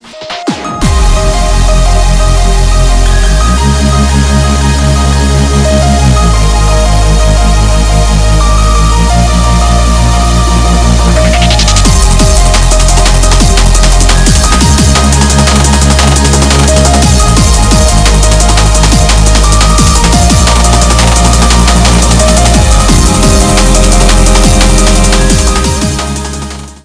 • Качество: 320, Stereo
без слов
клавишные
drum n bass
Энергичная мотивирующая мелодия